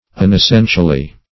unessentially - definition of unessentially - synonyms, pronunciation, spelling from Free Dictionary
unessentially - definition of unessentially - synonyms, pronunciation, spelling from Free Dictionary Search Result for " unessentially" : The Collaborative International Dictionary of English v.0.48: Unessentially \Un`es*sen"tial*ly\, adv. In an unessential manner.